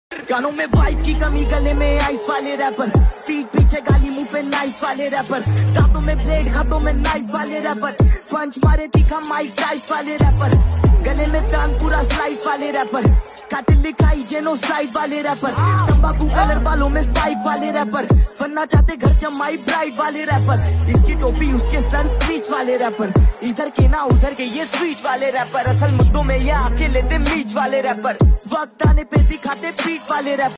High-Quality Free Ringtone